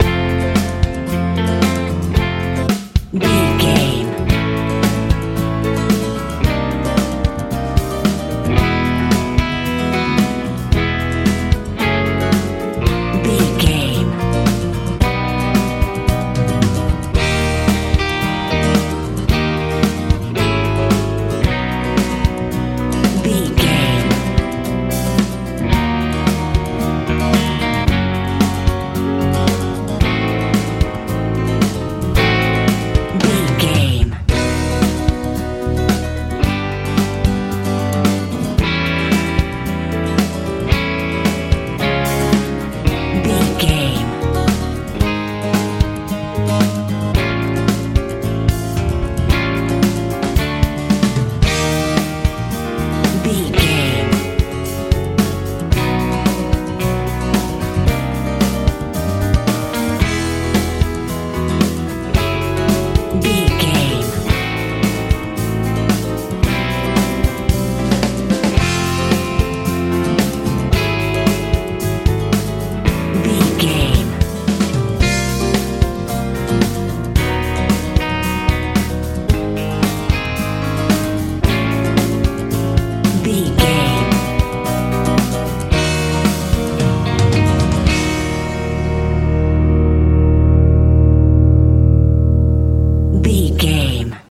lite pop feel
Ionian/Major
bright
cool
piano
electric guitar
bass guitar
drums
fun